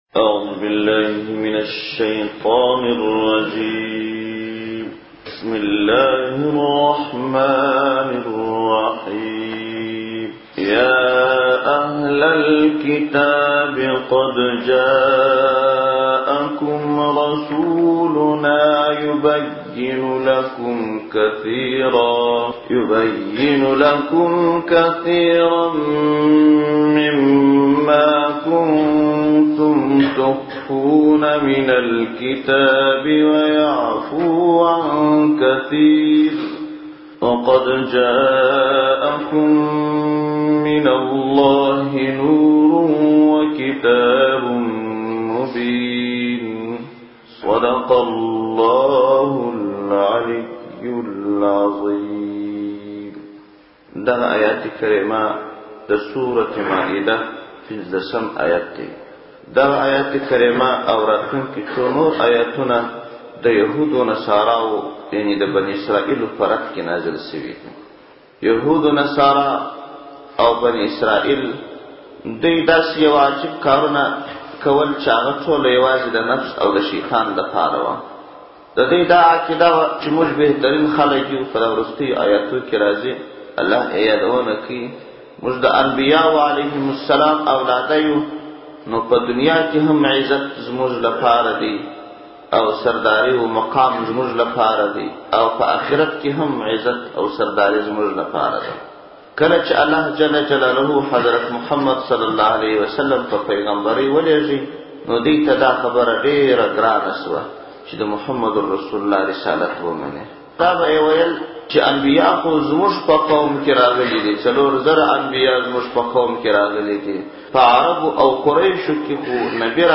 پښتو – د قرآن کریم تفسیر او ترجمه